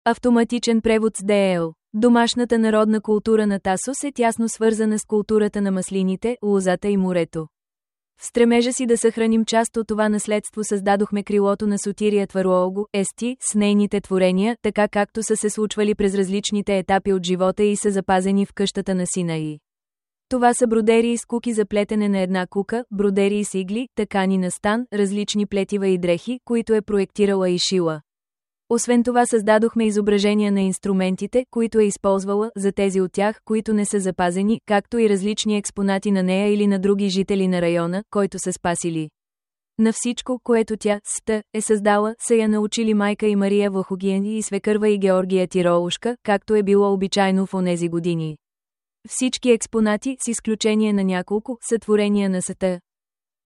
Обиколка с аудиогид